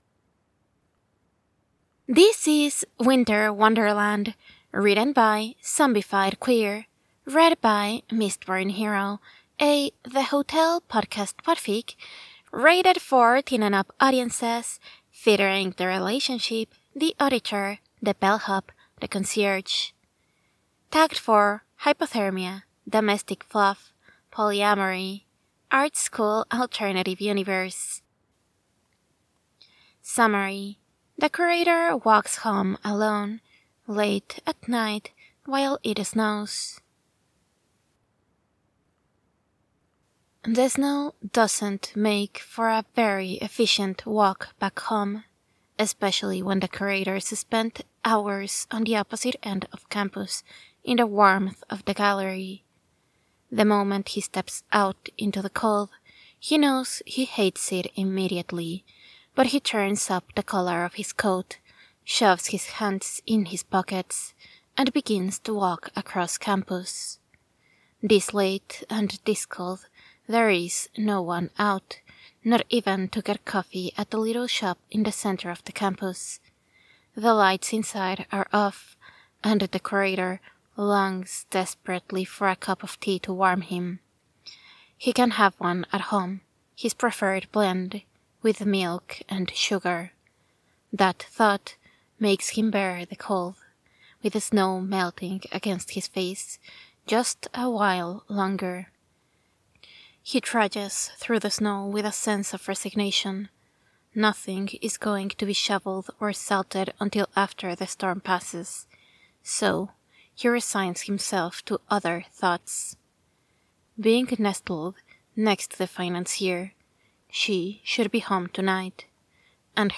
music under narration: download mp3: here (r-click or press, and 'save link') [19 MB, 00:13:09]
music only in transitions: download mp3: here (r-click or press, and 'save link') [18 MB, 00:12:05]